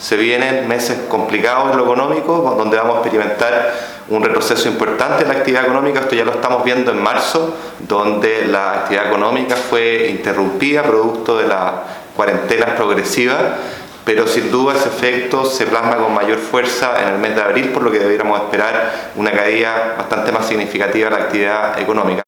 Así lo señaló el ministro de Hacienda, Ignacio Briones, advirtiendo que vienen “meses complicados” para la economía.